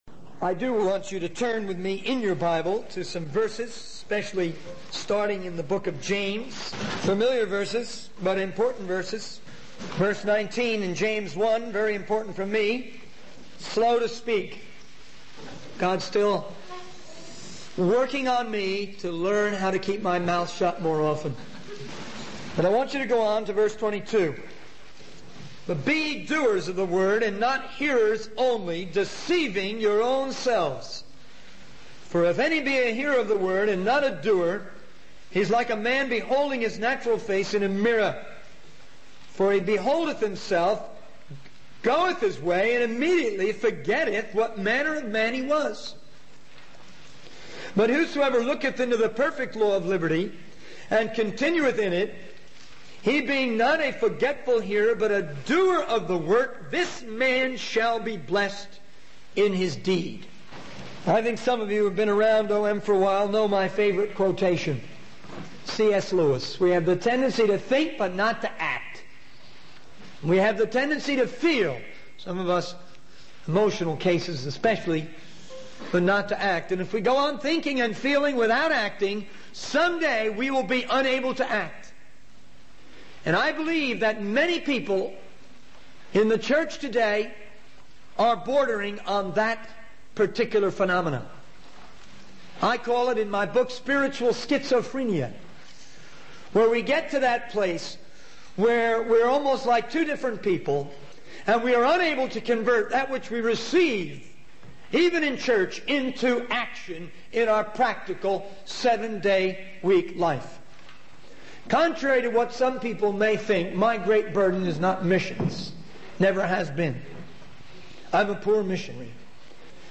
In this sermon, the speaker emphasizes the importance of finding one's own style and approach to preaching the word of God. He encourages the audience to be actively involved in spreading the missionary vision and taking part in evangelism. The speaker shares personal experiences of delivering powerful messages to churches in South America and Mexico, urging them to grow and send out their own missionaries.